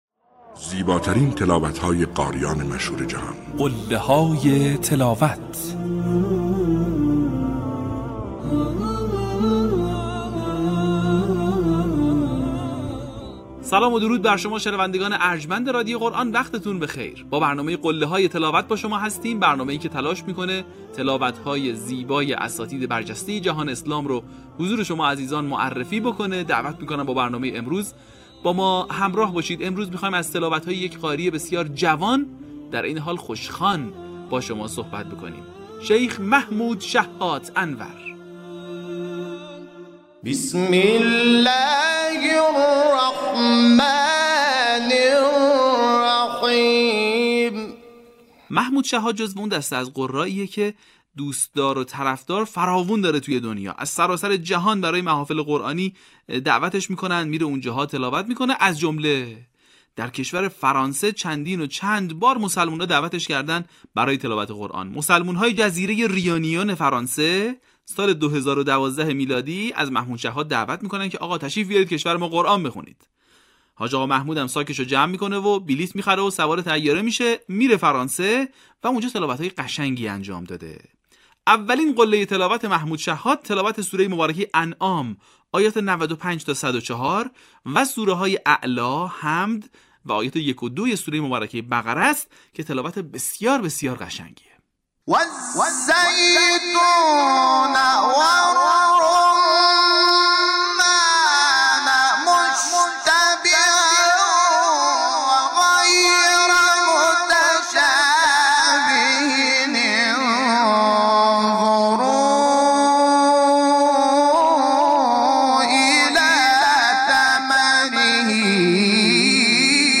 در قسمت سی‌ام فراز‌های شنیدنی از تلاوت‌های محمود شحات انور را می‌شنوید.
برچسب ها: محمود شحات انور ، قله های تلاوت ، تلاوت ماندگار ، تلاوت تقلیدی